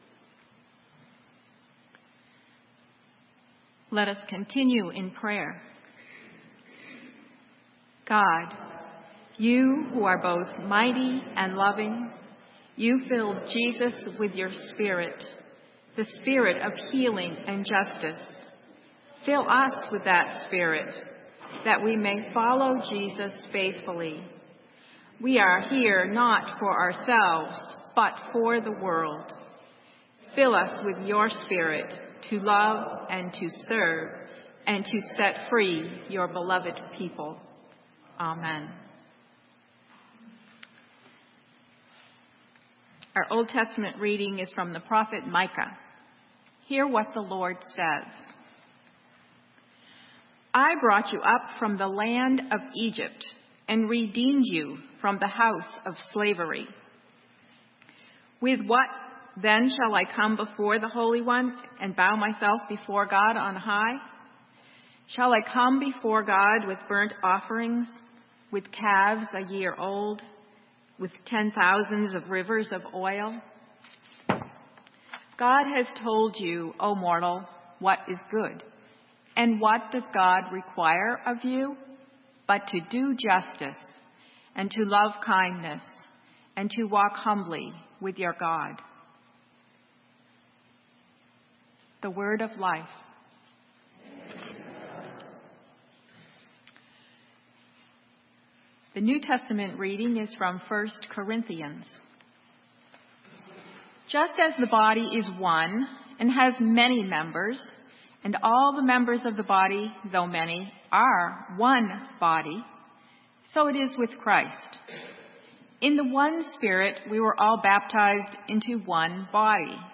Sermon:The Spirit of God is upon me...